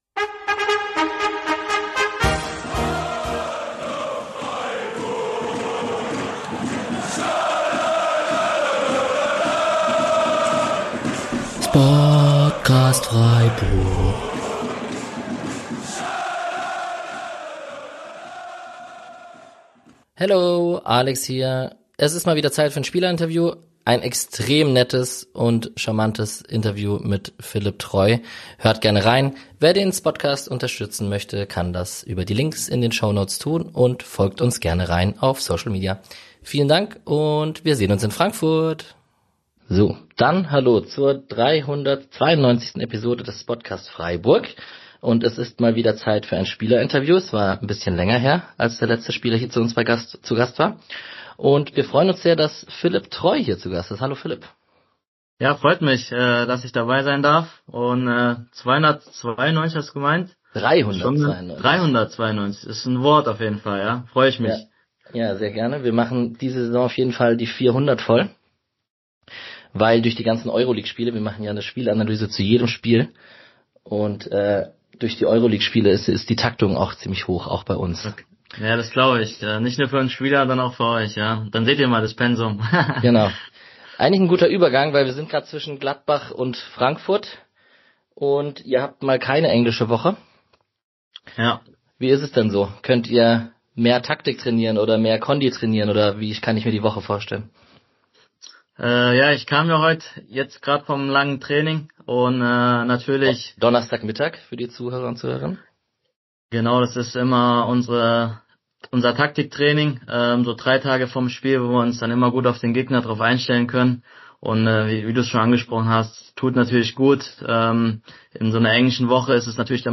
Karriere, SC-Rückkehr, Mannschaftsrat - Interview: Philipp Treu ~ Spodcast Freiburg Podcast